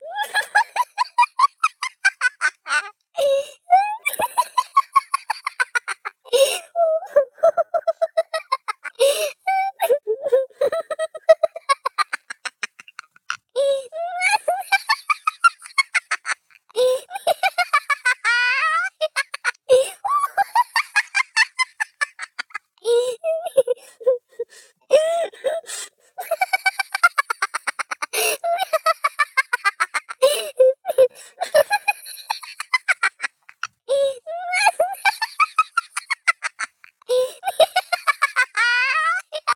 思わず笑顔になっちゃう楽しい笑い声の着信音・アラーム音。
思わず笑顔になっちゃう楽しい笑い声の着信音・アラーム音。笑いが連鎖して、周りまで楽しい気分にしてくれるかも